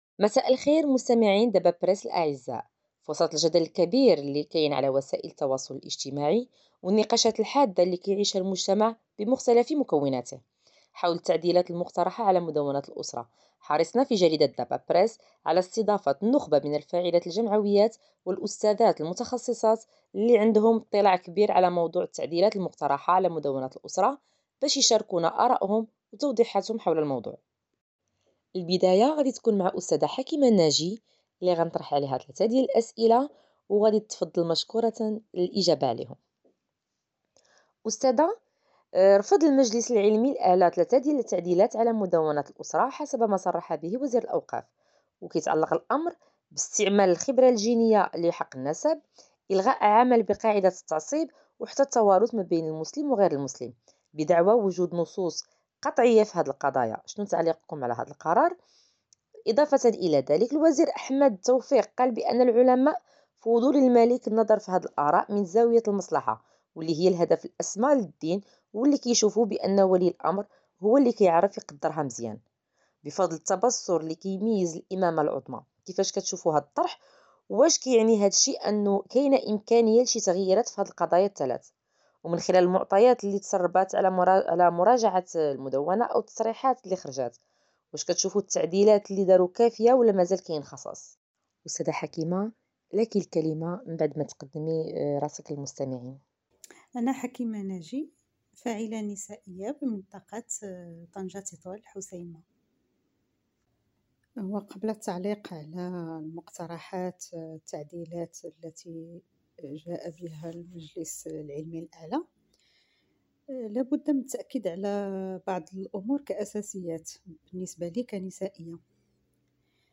وفيما يلي نص الحوار …